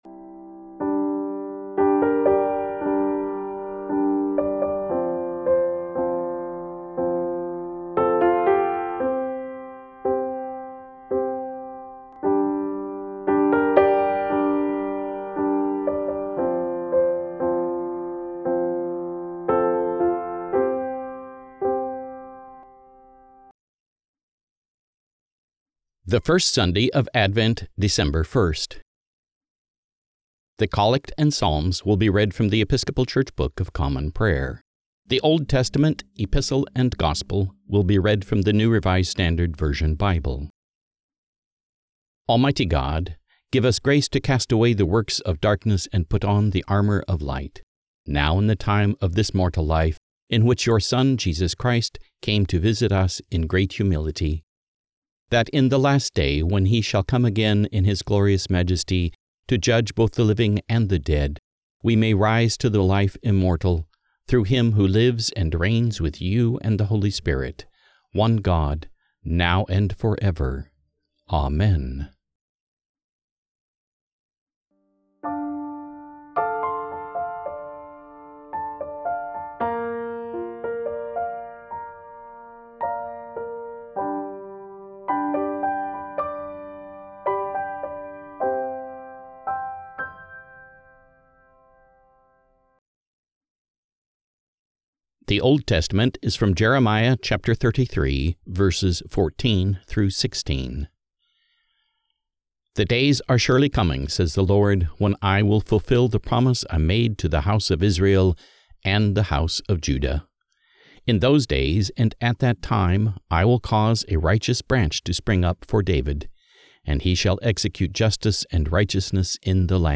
The Collect and Psalms will be read from The Episcopal Church Book of Common Prayer
The Old Testament, Epistle and Gospel will be read from The New Revised Standard Version Bible